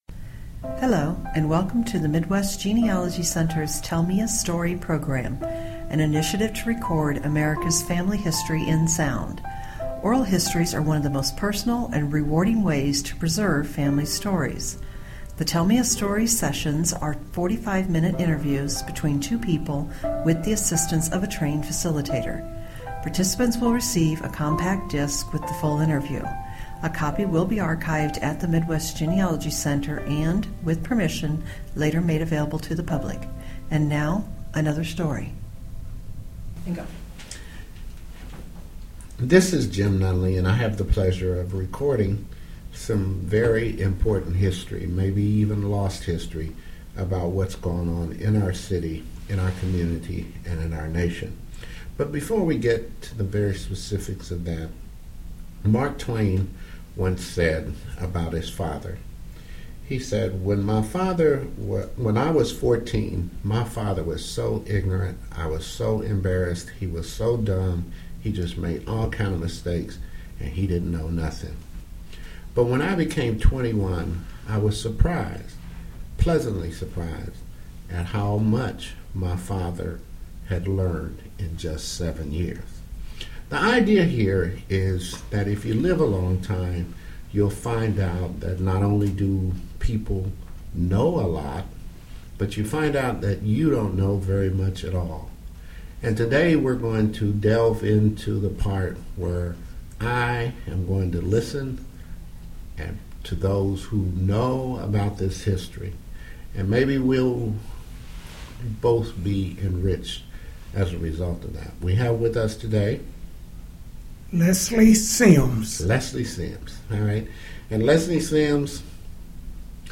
Interviews Segregation--Missouri--Kansas City--20th century